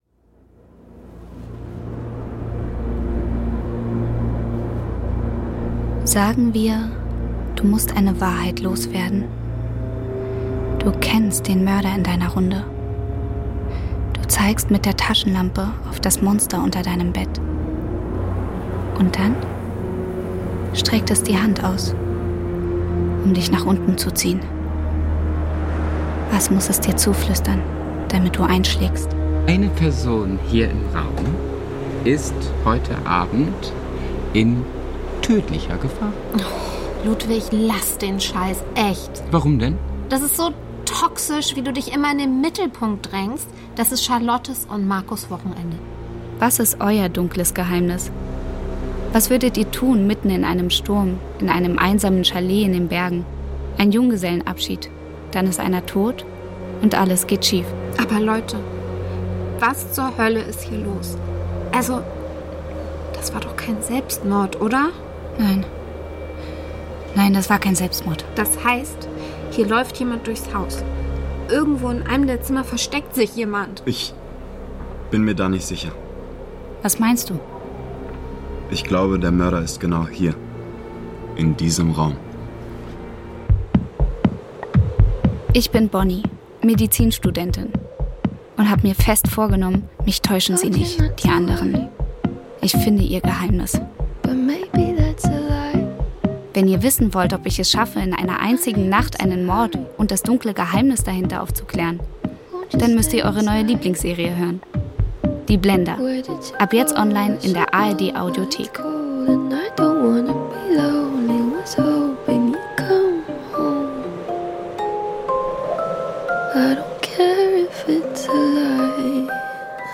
Dezember ~ Die Blender – Crime-Hörspiel-Serie Podcast